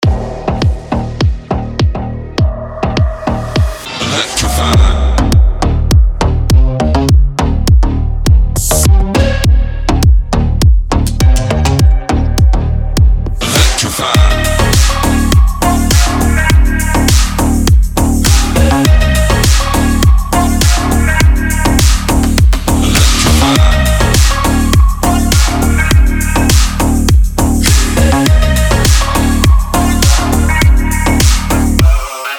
• Качество: 256, Stereo
Electronic